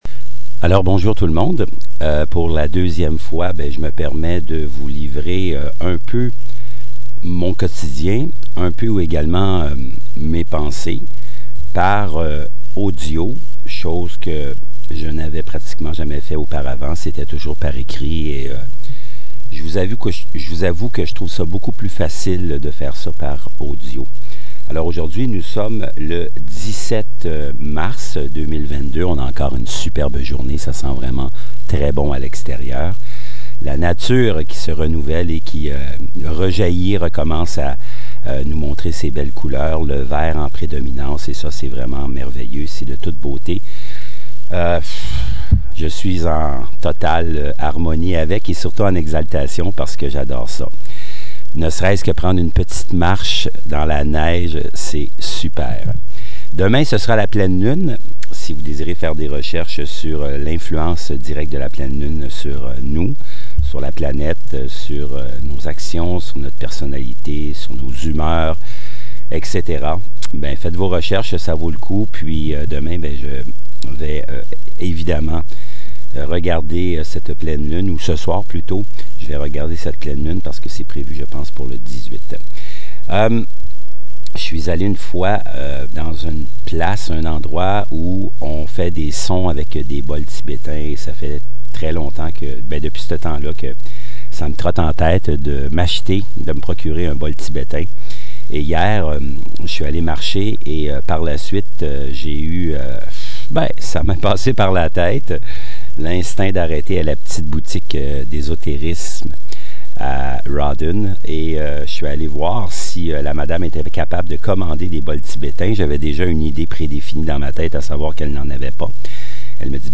de propos bien mijotés…mais pour des raisons hors de contrôle je me limite à ce petit sonore qui a été fait sur le coin de la table… en toute simplicité! 🙂
Bol tibétain  et temps intemporel!
Le plus simple est de le faire sonner en le frappant comme un gong.
Quand on arrête le mouvement, le son disparaît progressivement.
bol-thibetain.mp3